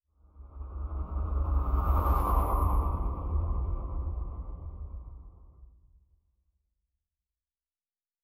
Distant Ship Pass By 5_6.wav